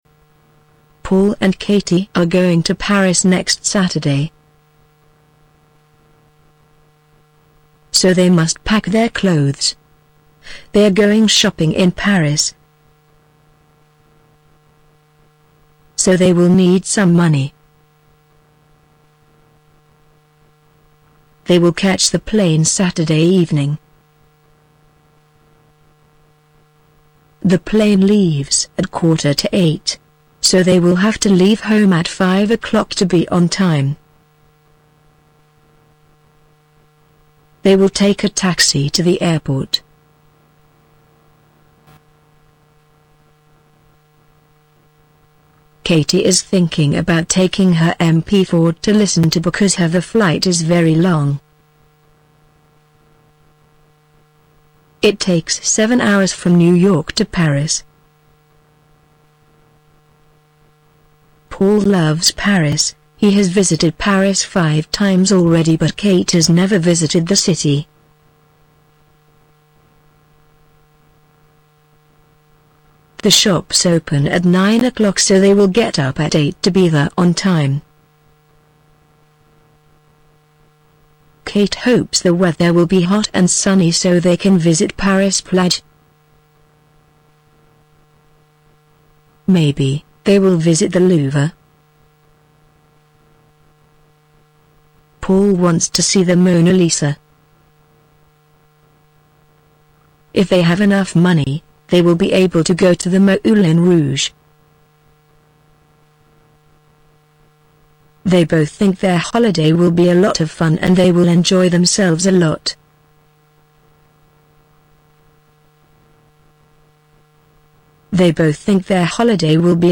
1) Listen to the woman speaking and take notes.